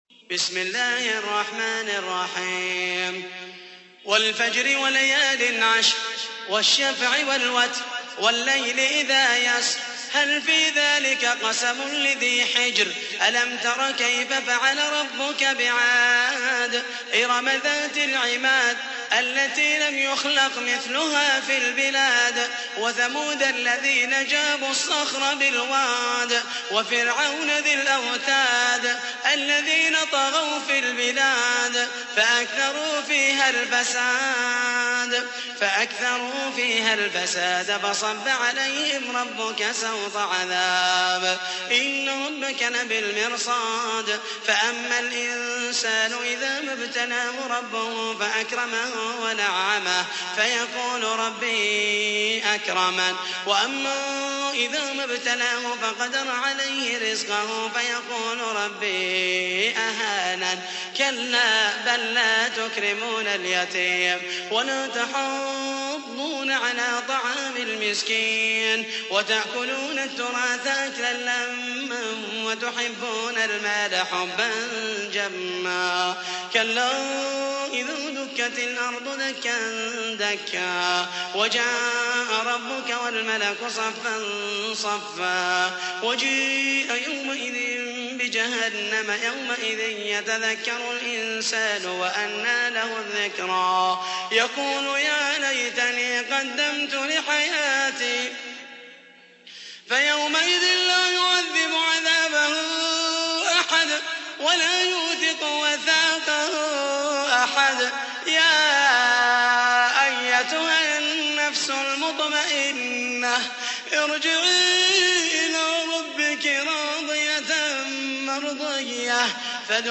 تحميل : 89. سورة الفجر / القارئ محمد المحيسني / القرآن الكريم / موقع يا حسين